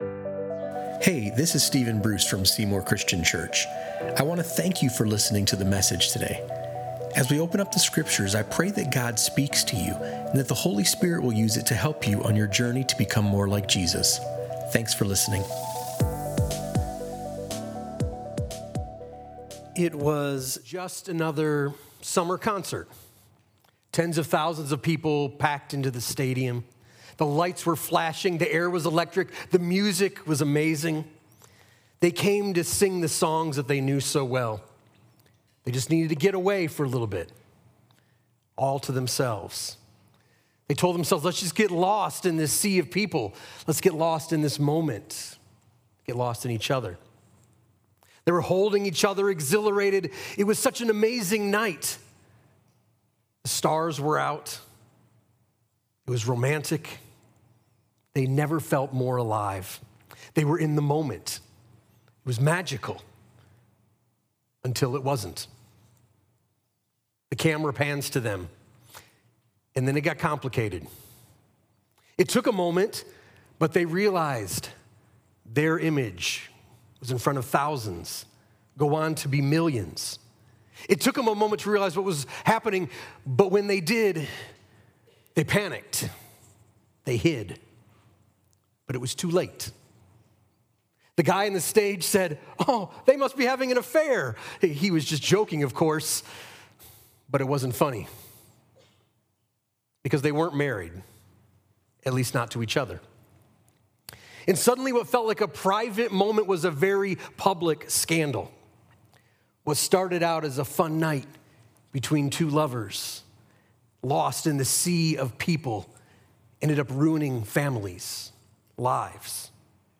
Using a real-life story that recently went viral and a fictitious tale told by a father to his son, this sermon highlights how easily we can be pulled toward the wrong path—often while telling ourselves we’re still in control. Whether it’s lust, greed, the need for control, or the pursuit of approval, Lady Folly knows how to dress it up.